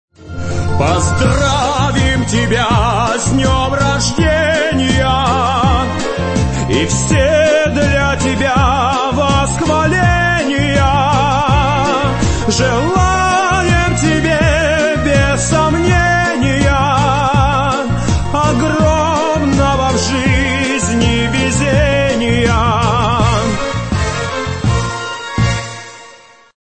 prikol-nikolaj-baskov-pozdravlyaet.mp3